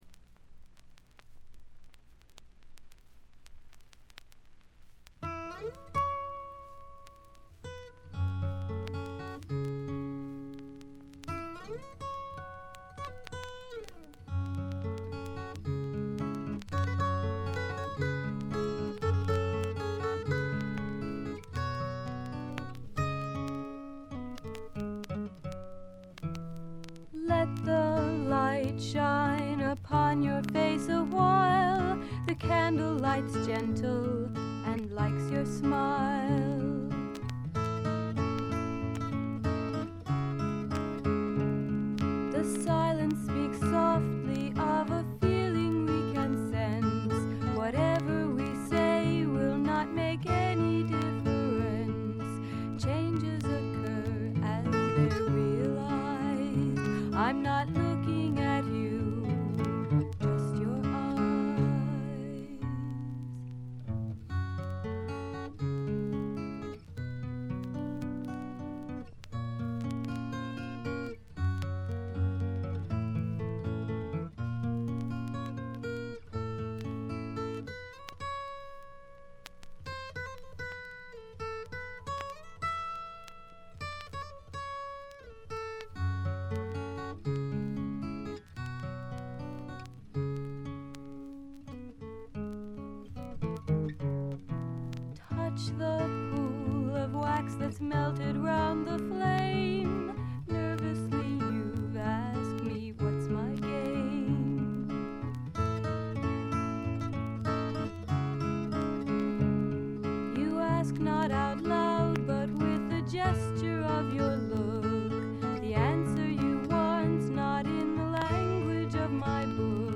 B2チリプチ多め。
昔からアシッドフォークの定番扱いされてきた名盤です。
試聴曲は現品からの取り込み音源です。